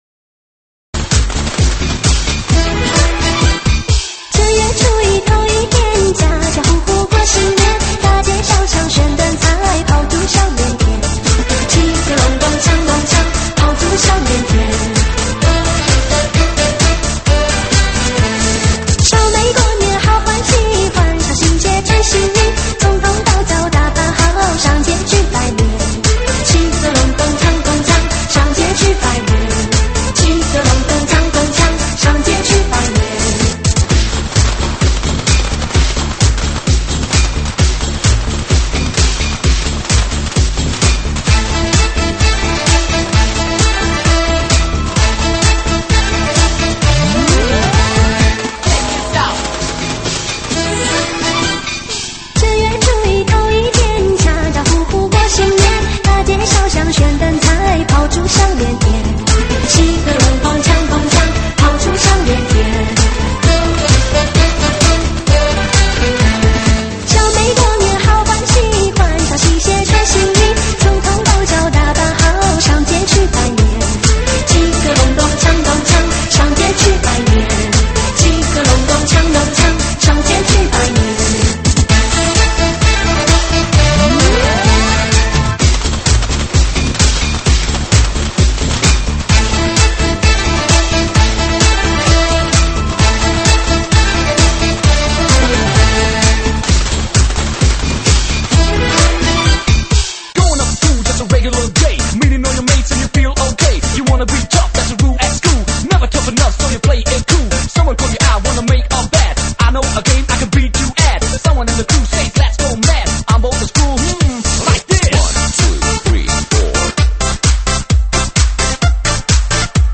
新年喜庆